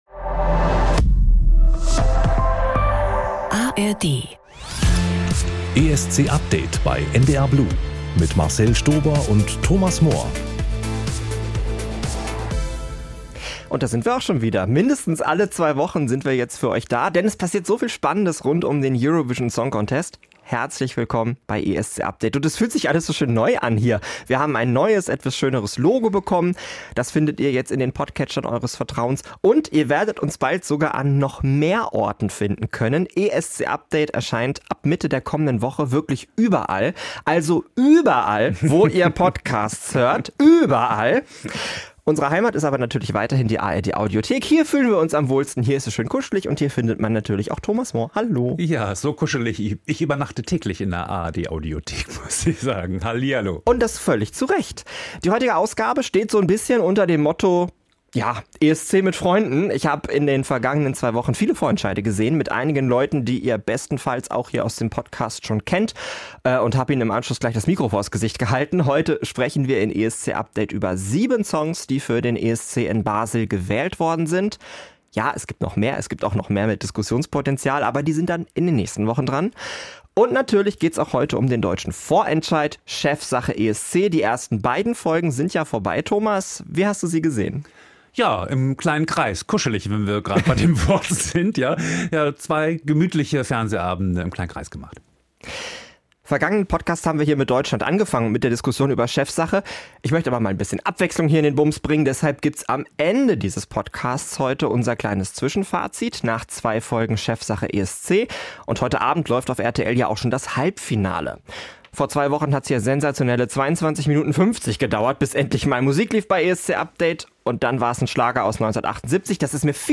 Und dann waren wir auch noch live vor Ort beim norwegischen ESC-Vorentscheid in Oslo - und auf der Aftershowparty!